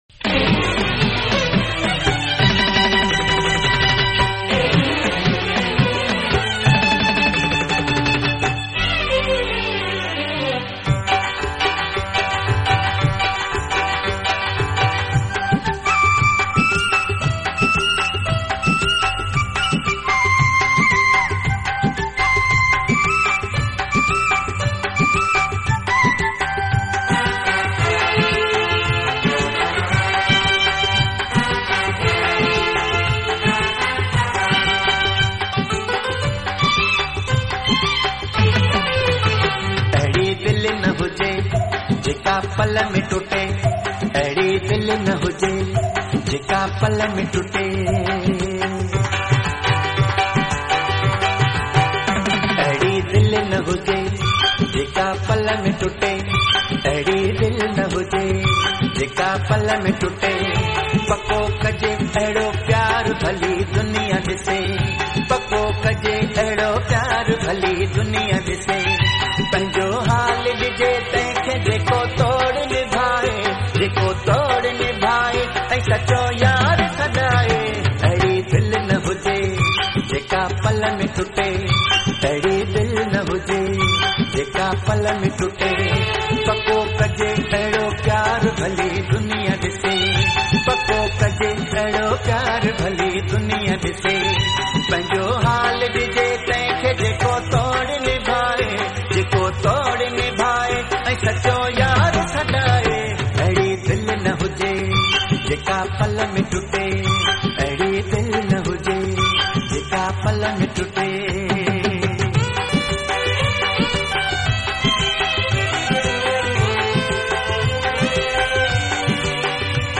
Sindhi Song